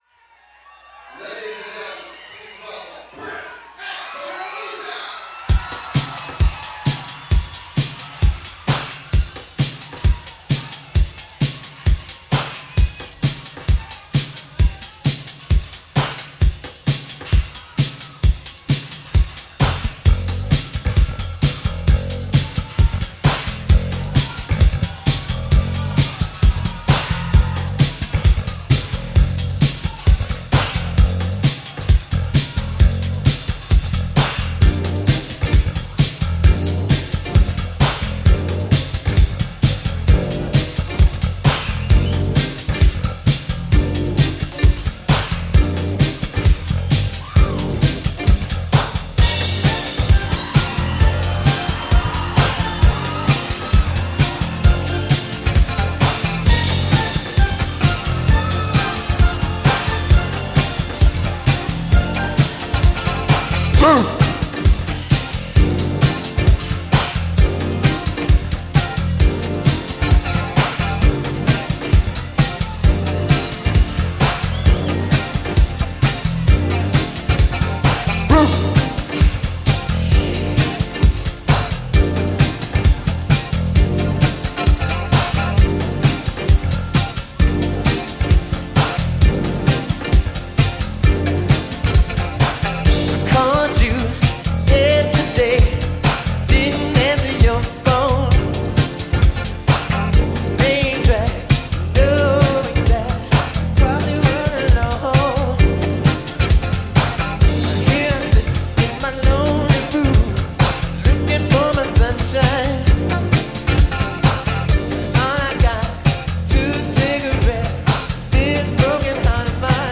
Birthday concert
Birthday Concert – First Avenue, Minneapolis